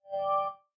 dock.ogg